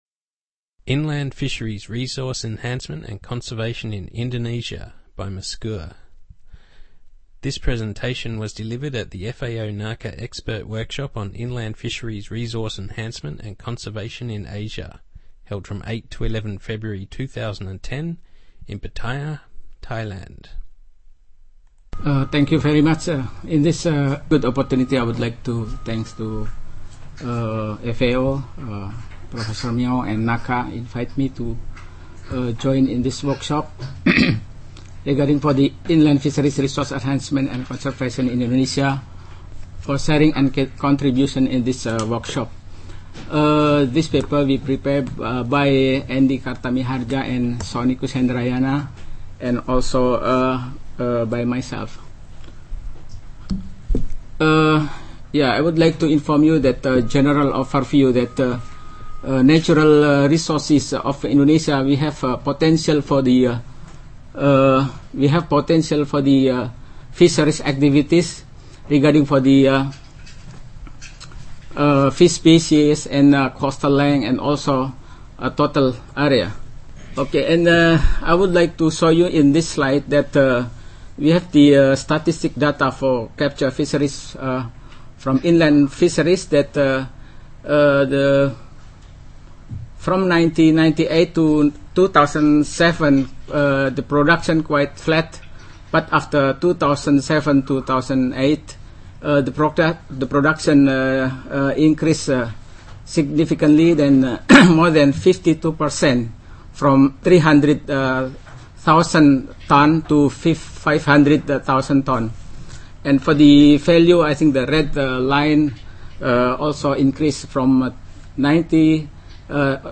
Presentation on inland fisheries resource enhancement and conservation in Indonesia